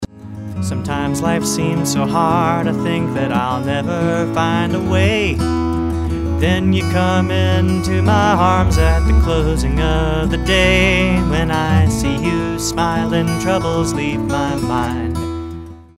A very nice mixture of fingerstyle acoustic guitar